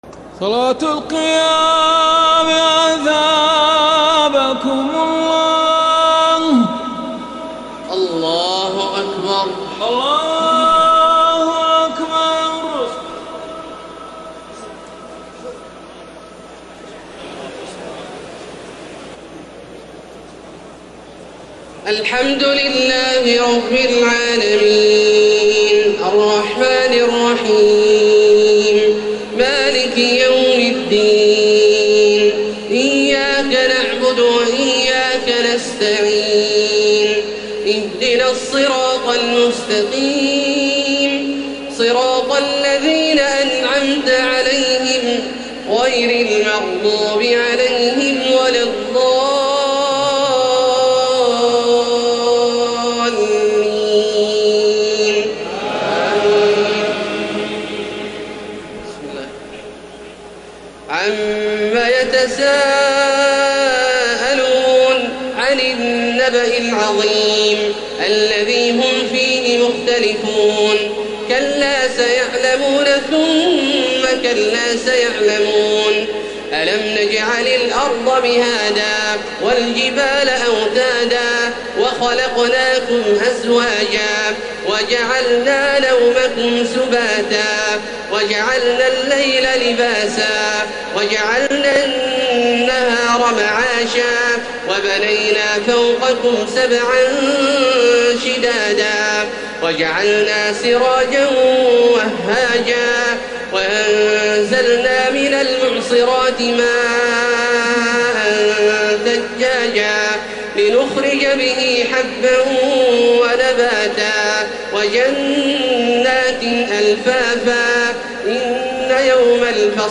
تراويح ليلة 29 رمضان 1432هـ من سورة النبأ الى الليل Taraweeh 29 st night Ramadan 1432H from Surah An-Naba to Al-Lail > تراويح الحرم المكي عام 1432 🕋 > التراويح - تلاوات الحرمين